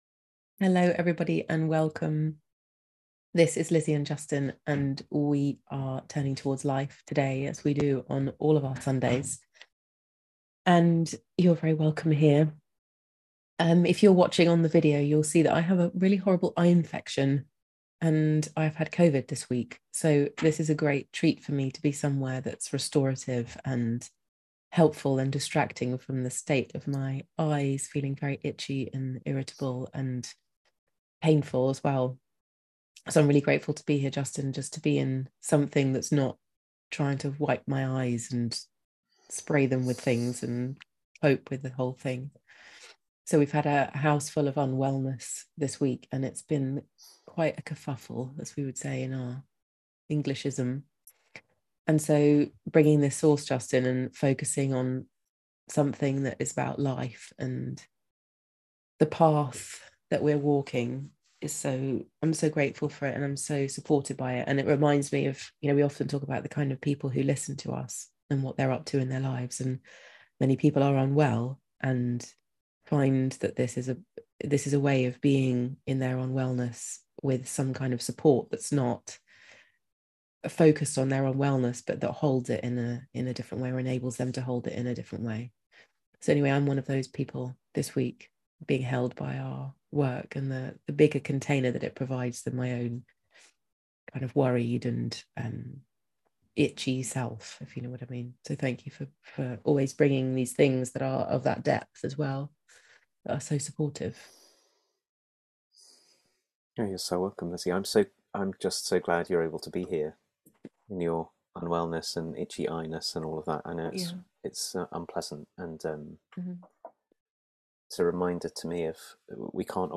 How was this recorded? live 30 minute conversation